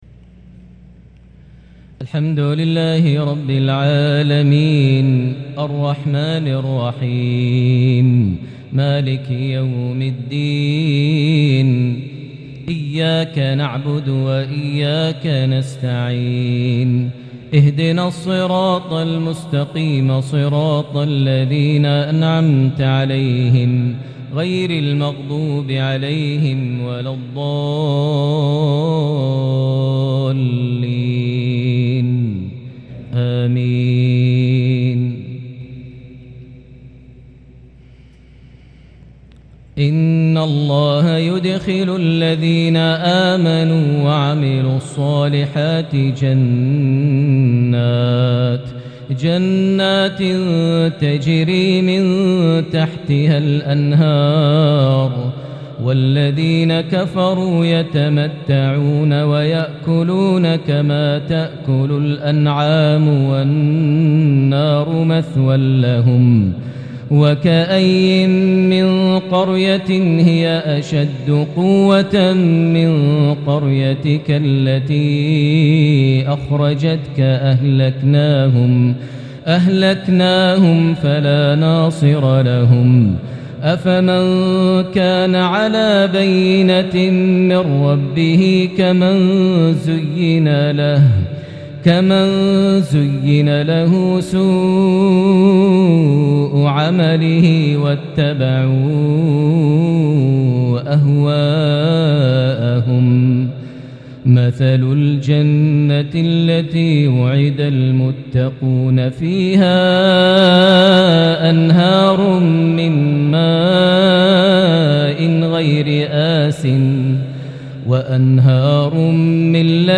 صلاة العشاء من سورة محمد 12-24 | 2 ذو القعدة 1443هـ| lsha 1-6-2022 prayer fromSurah Muhammad 12-24 > 1443 🕋 > الفروض - تلاوات الحرمين